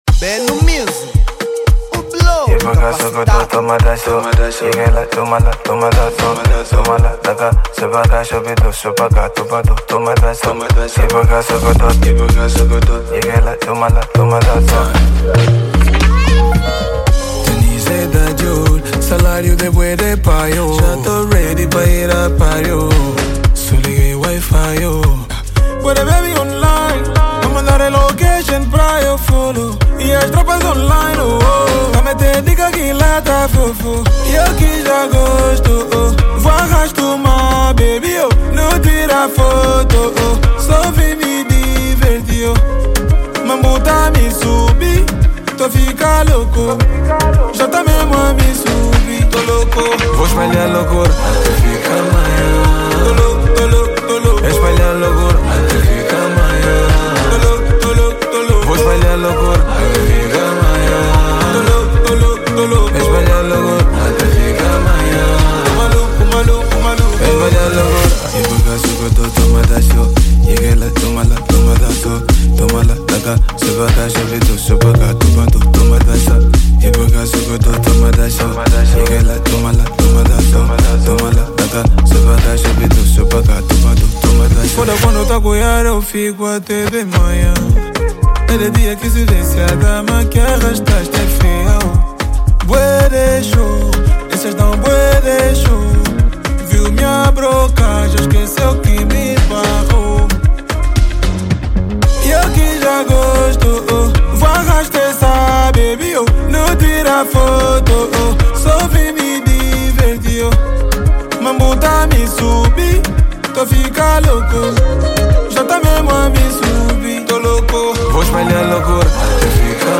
Género : Amapiano